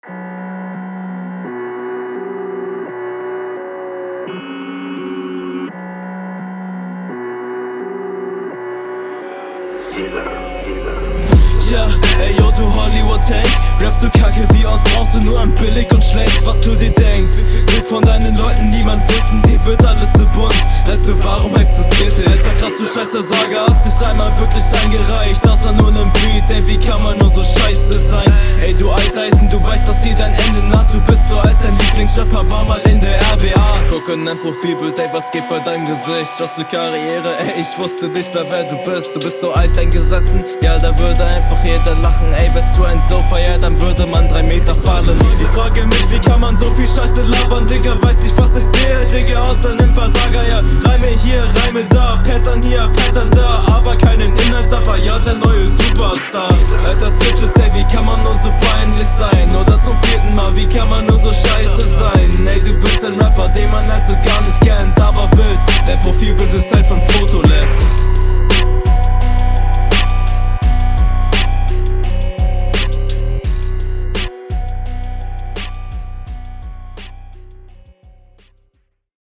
Sehr cooler Stimmeinsatz, klingt sehr gut Du sprichst irgendwie Angriffsflächen immer nur an ("du bist …